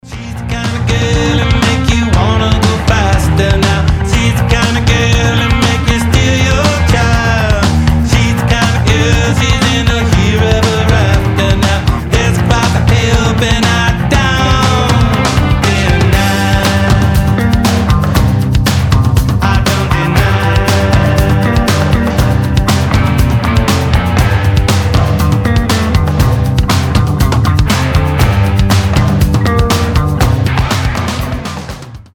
• Качество: 320, Stereo
Alternative Rock
Funk Rock